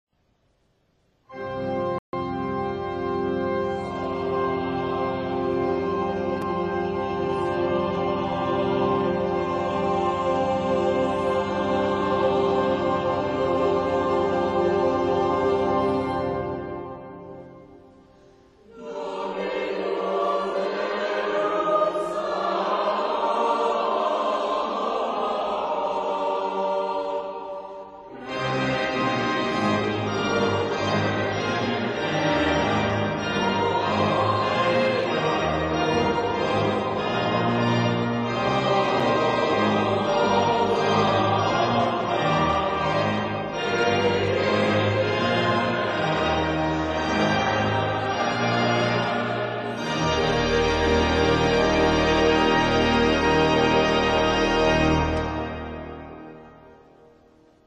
Sanctus, Agnus Dei, chœur à 4 voix mixtes et orgue (1968).